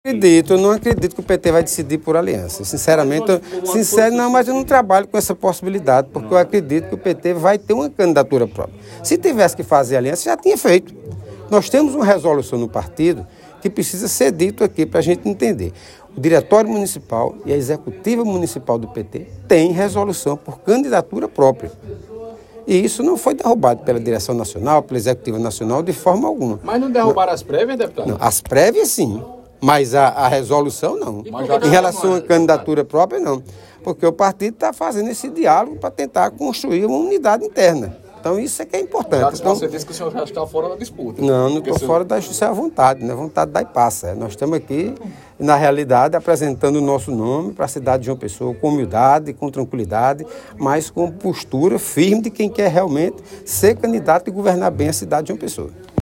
Abaixo a fala do deputado estadual Luciano Cartaxo.